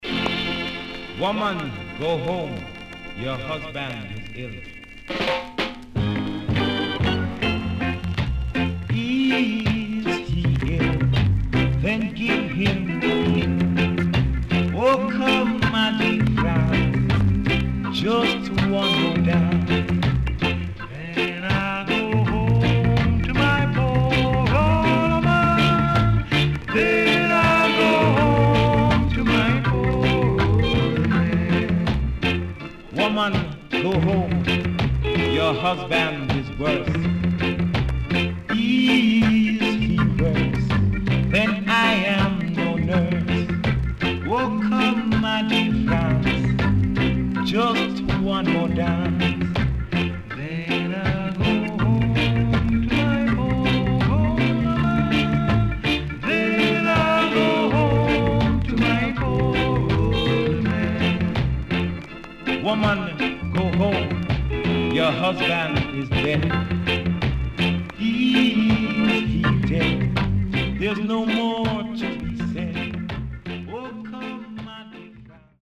ROCKSTEADY
W-Side Good Rocksteady Vocal
SIDE A:全体的にチリノイズがあり、少しプチノイズ入ります。